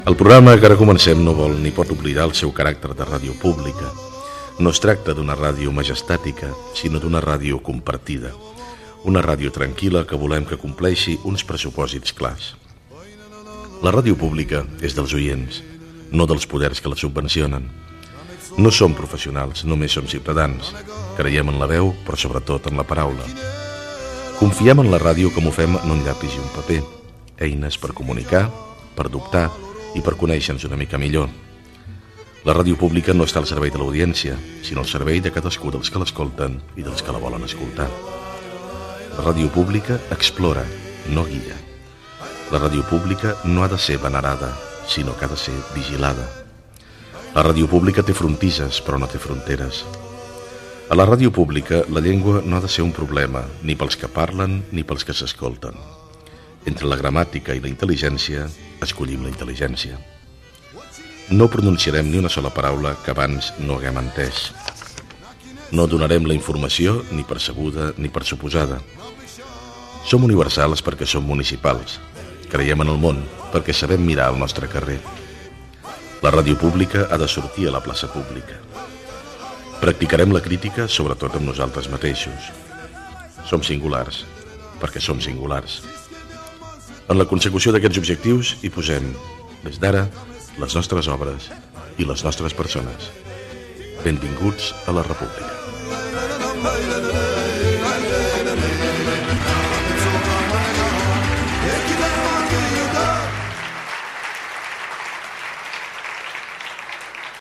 Info-entreteniment
Informatiu i magazín emès de 2000 a 2005 a COMRàdio.